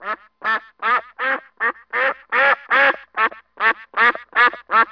دانلود آهنگ حیوانات جنگلی 38 از افکت صوتی انسان و موجودات زنده
جلوه های صوتی
دانلود صدای حیوانات جنگلی 38 از ساعد نیوز با لینک مستقیم و کیفیت بالا